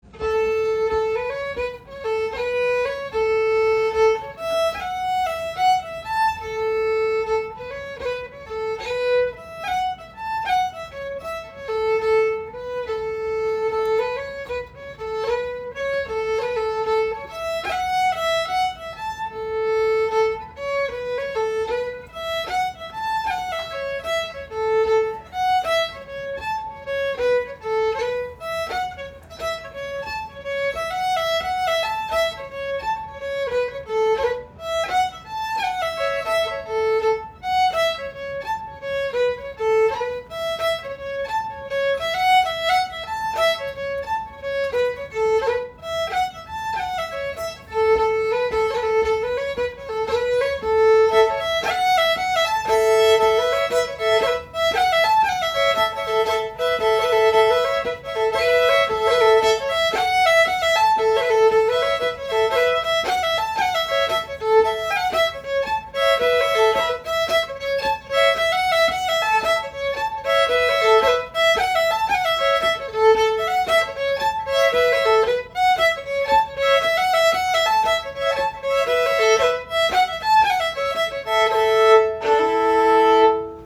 Sessions are open to all instruments and levels, but generally focus on the melody.
Apparently, this was originally a pipe march in 6/8, but we are playing this as a jig.
Composer Traditional Type Jig Key A mix Recordings